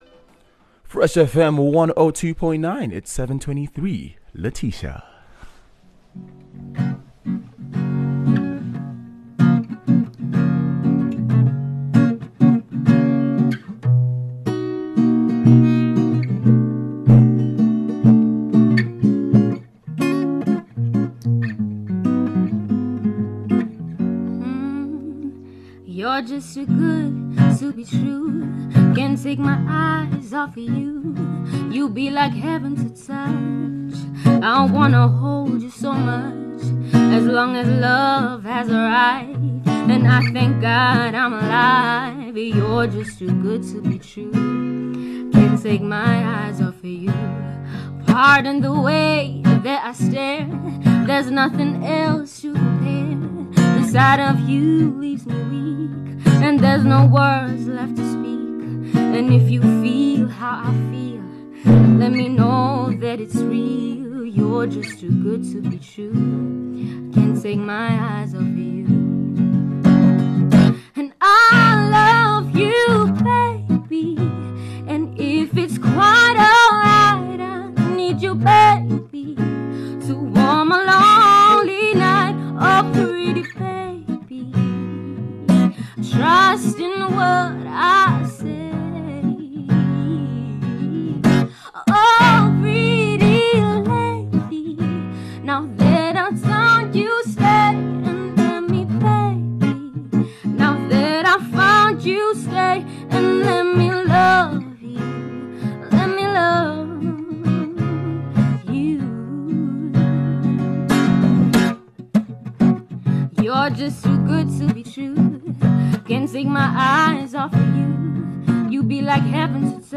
in studio
guitar
majestical vocals